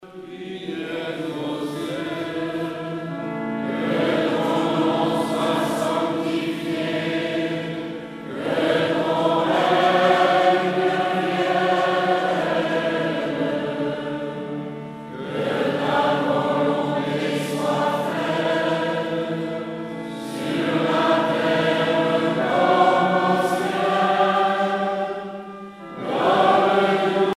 prière, cantique
Pièce musicale éditée